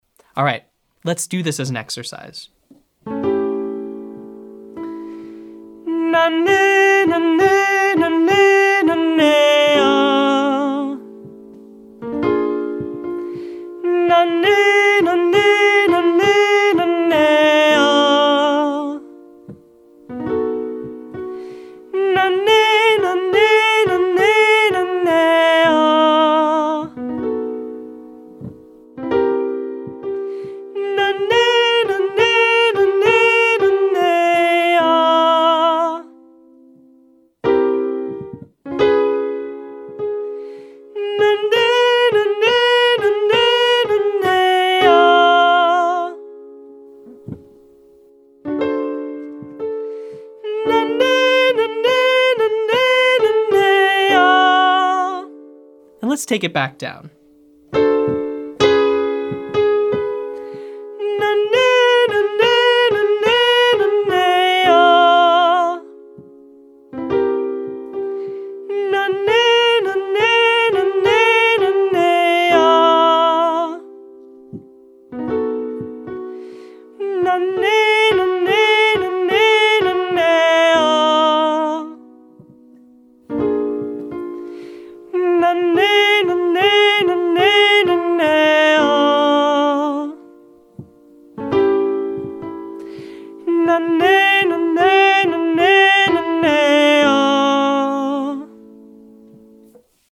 First let’s get into a balanced mix by switching chest and head voice more rapidly like we did in day 9. For this one I’m bringing back our fun groove.
Now start working the groove a little faster as we get more comfortable moving back and forth between these two spots.